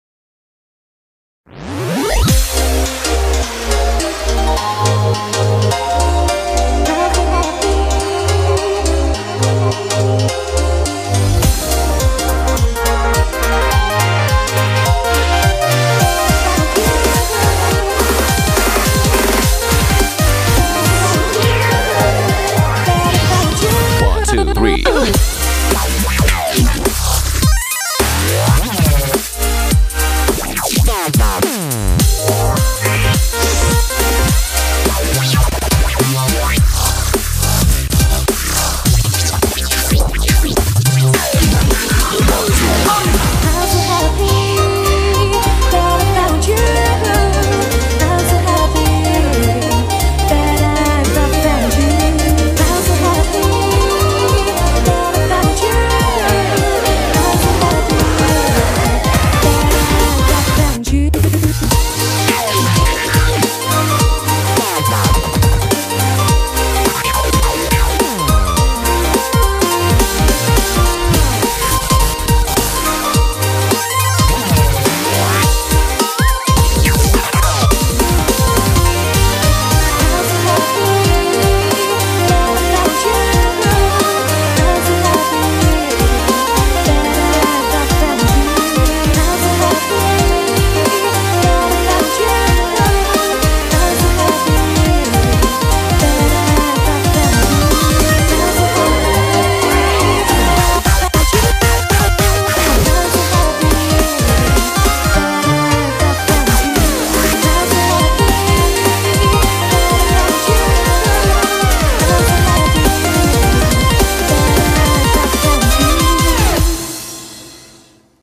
BPM105
Audio QualityPerfect (High Quality)
With this remix incorporating swing rhythms